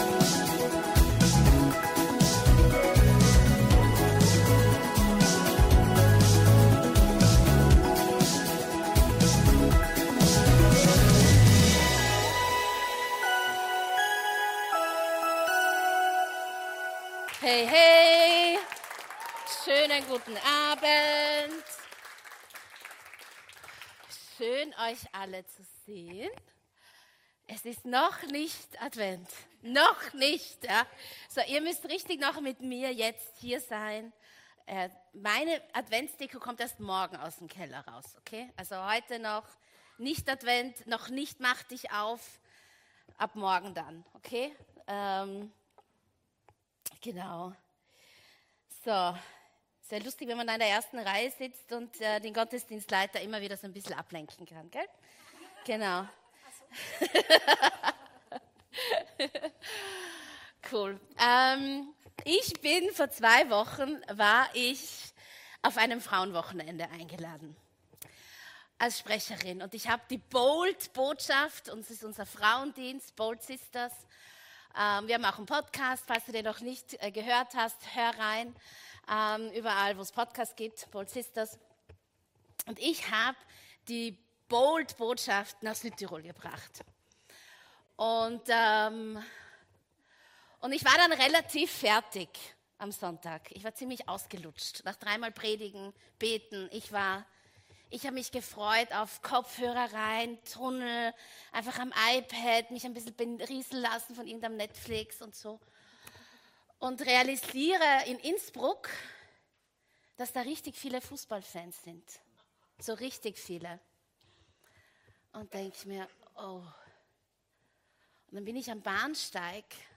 Gottesdienst live aus der LIFE Church Wien.